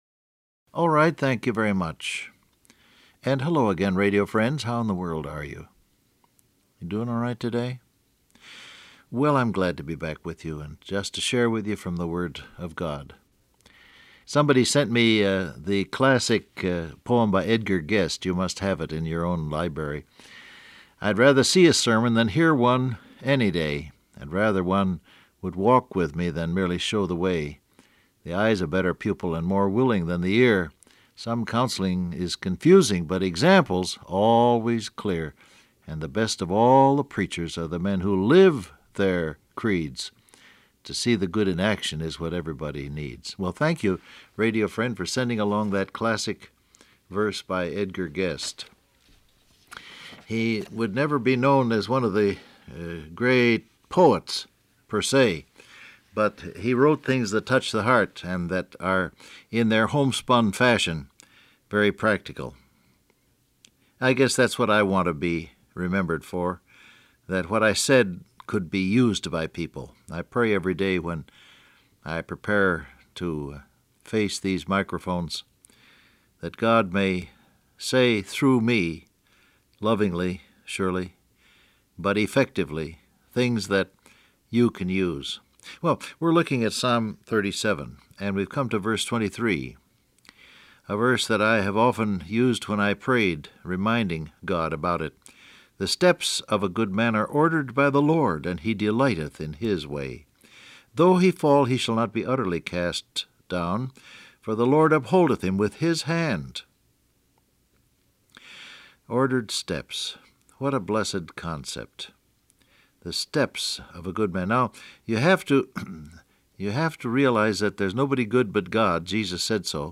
Download Audio Print Broadcast #7011 Scripture: Psalm 37:23 , Psalm 18:36 Topics: Pray , Trust , Goals , Confidence Transcript Facebook Twitter WhatsApp Alright, thank you very much and hello again, radio friends.